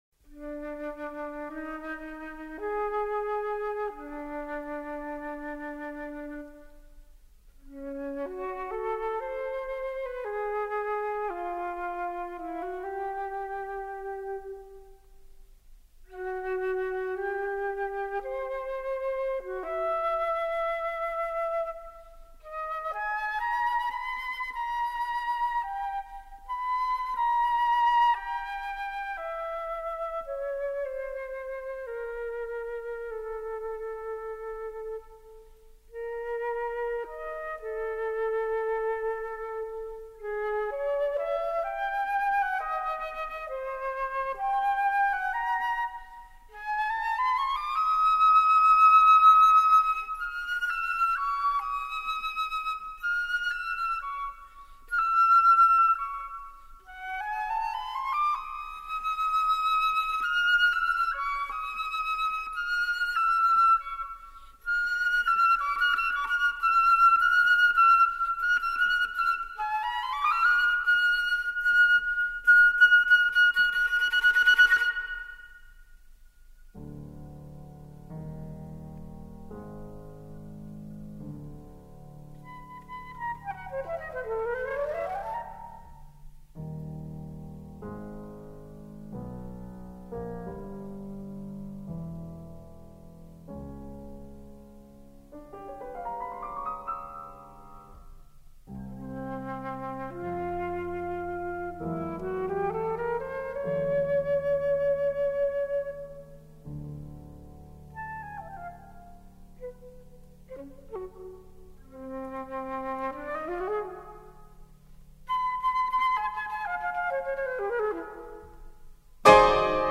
for flute and piano [1982]
flute
piano